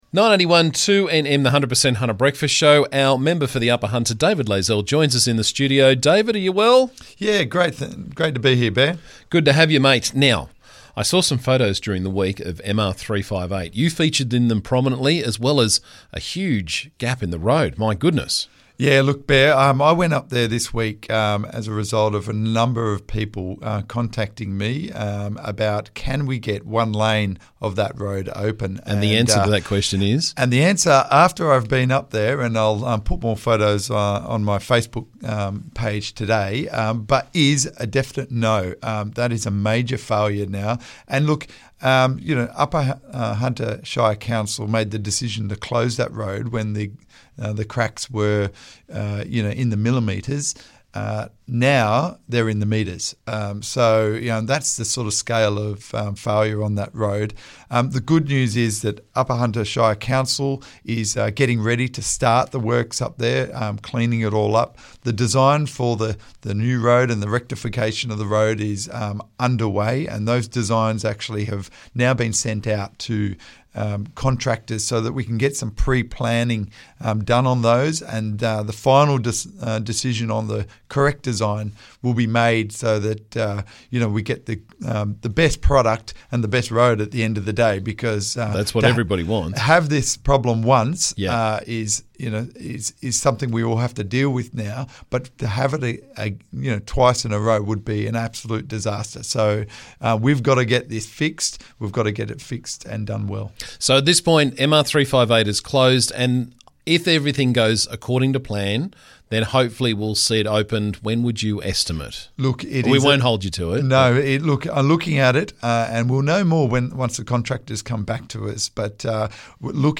Member for Upper Hunter David Layzell was on the show this morning to talk about the "Zombie" Petroleum Exploration Licences we discussed a while back and give us his take on MR358.